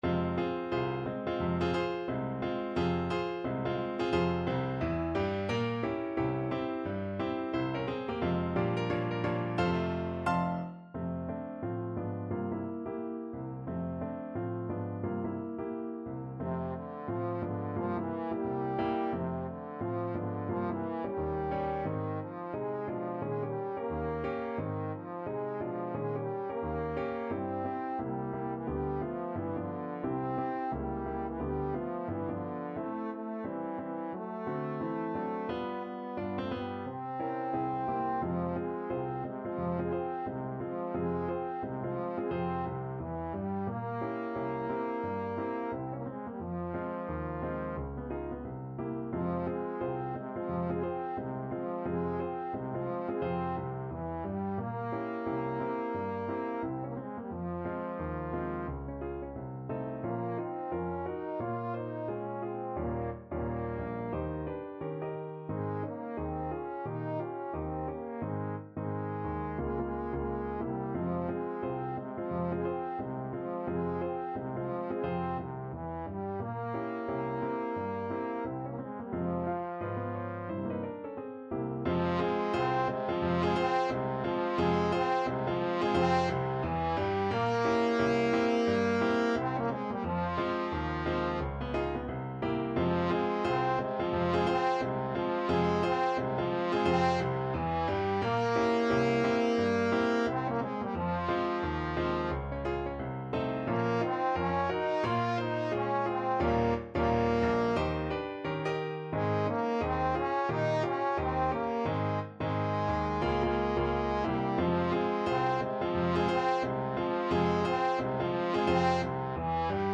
Trombone version
C4-F5
2/2 (View more 2/2 Music)
~ = 176 Moderato
Jazz (View more Jazz Trombone Music)
Rock and pop (View more Rock and pop Trombone Music)